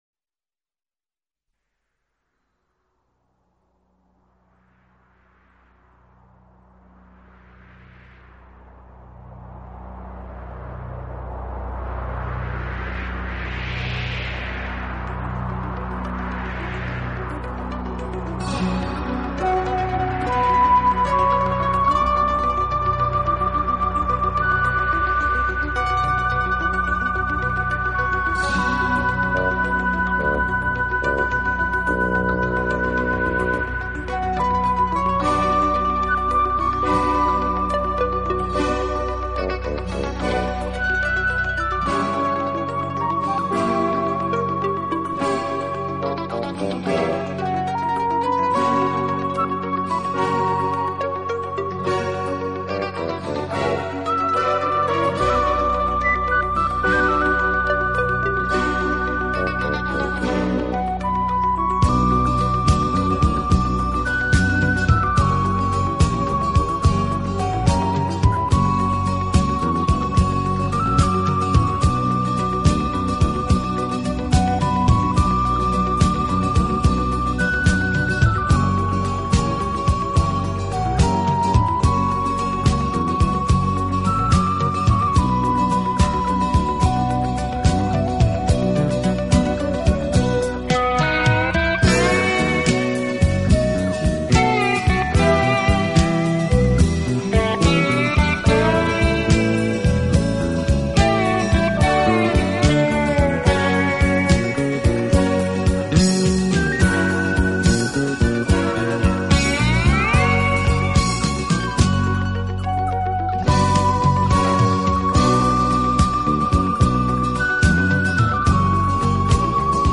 的音乐总是给人那幺大气、庄严和堂皇的感觉。